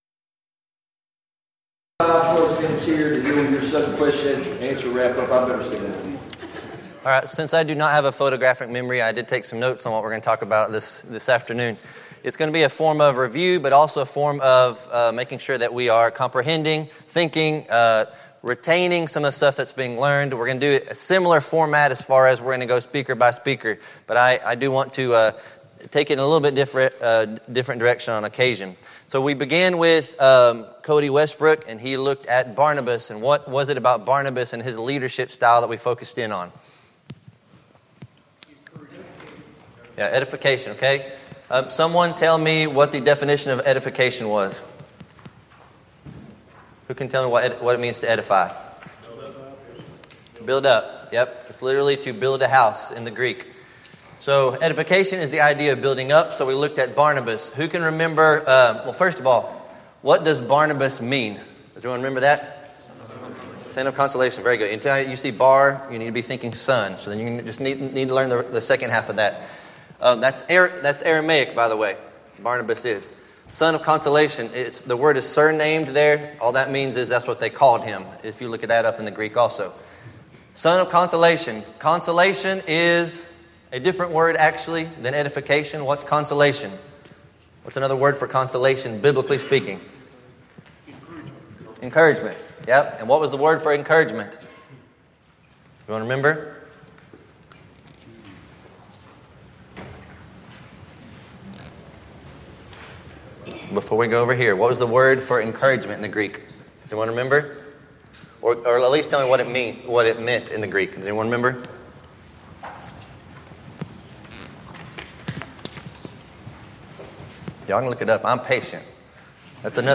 Event: 5th Annual Men's Development Conference Theme/Title: Repent & Be Converted